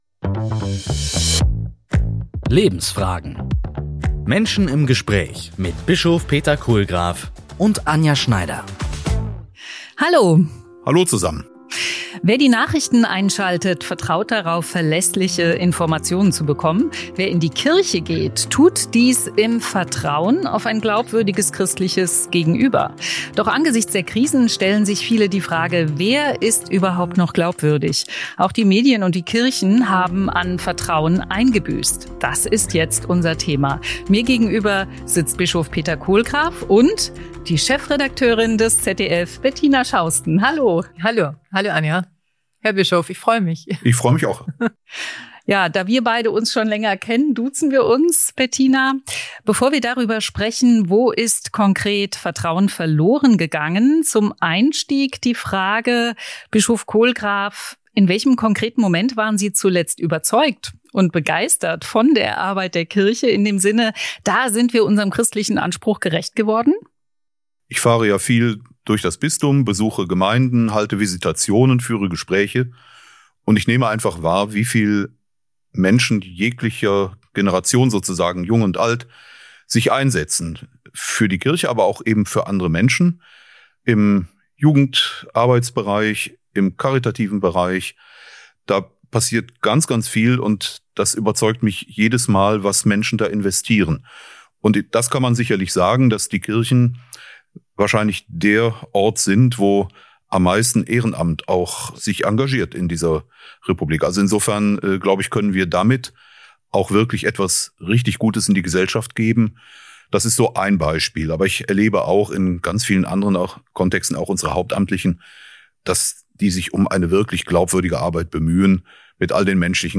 Lebensfragen - Menschen im Gespräch mit Bischof Kohlgraf